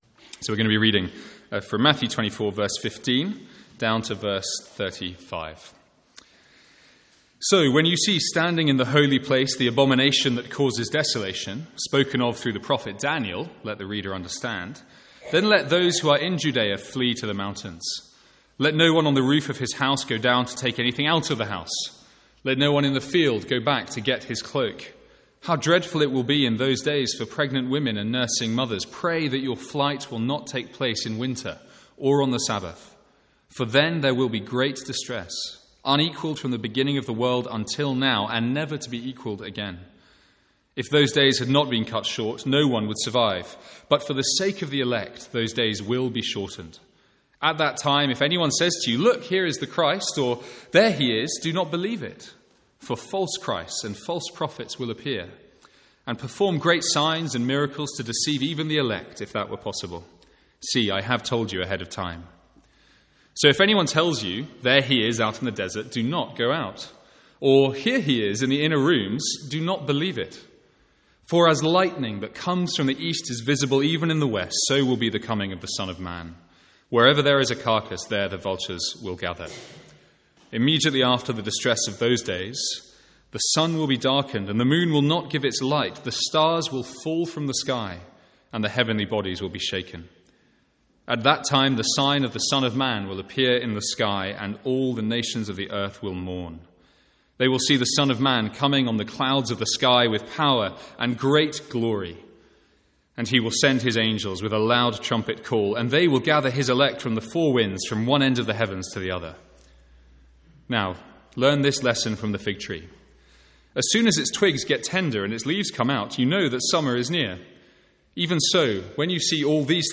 Sermons | St Andrews Free Church
From the Sunday morning series in Matthew's gospel.